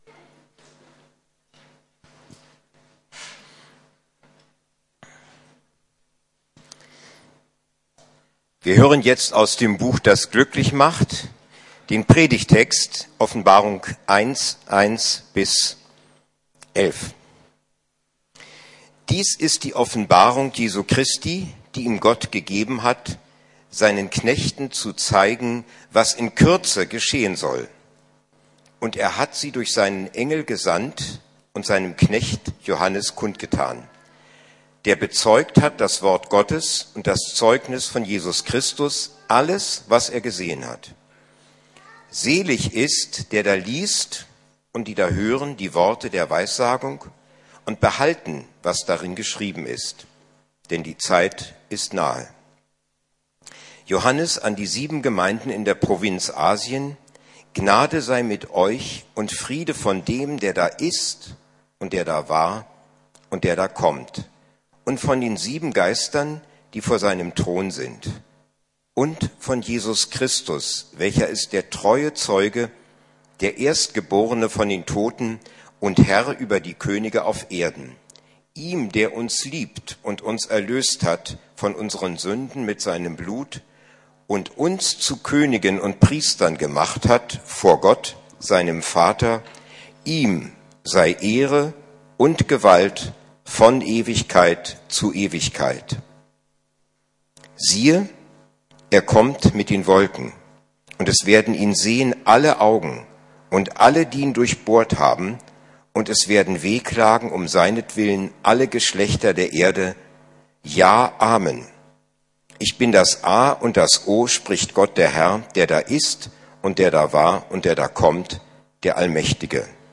Das Buch, das glücklich macht! Teil 1 - Wie kann ich es verstehen? ~ Predigten der LUKAS GEMEINDE Podcast